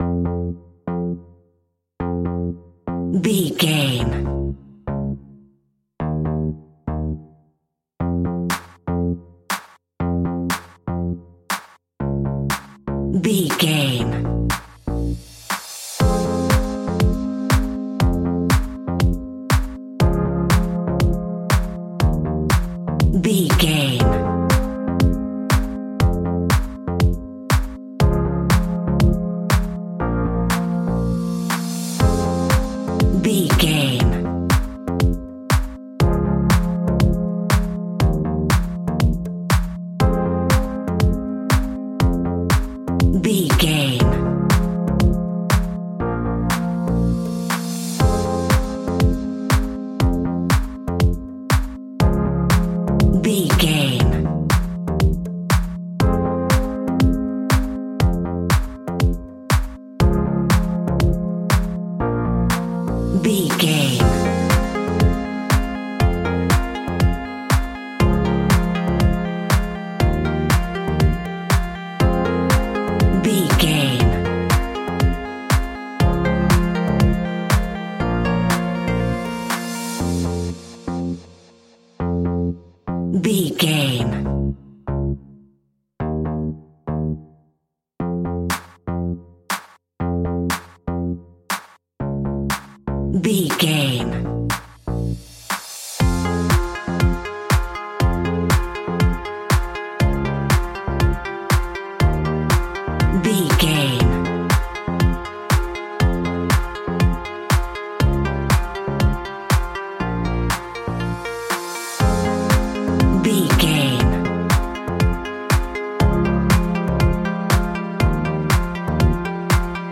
Aeolian/Minor
groovy
uplifting
driving
energetic
drum machine
synthesiser
funky house
deep house
nu disco
upbeat
synth bass
synth leads